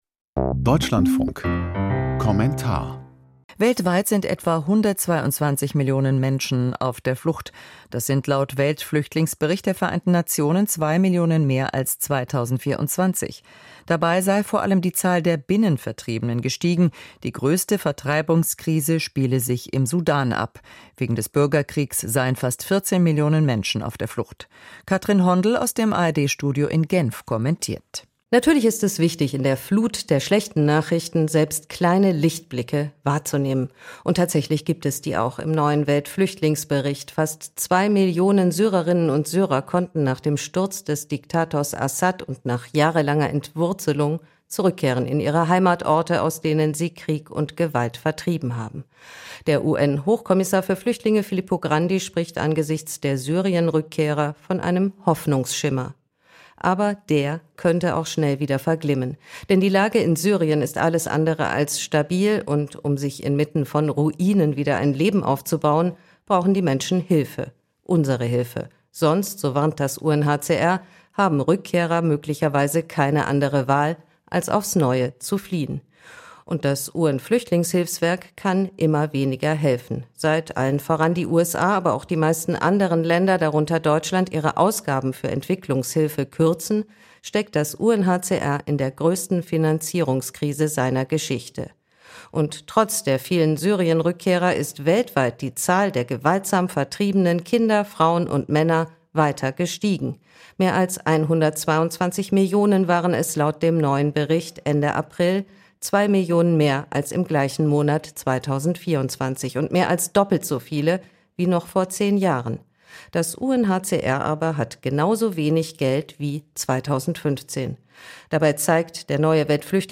Kommentar zum Weltflüchtlingsbericht: Es ist unverantwortlich, Hilfen zu kürzen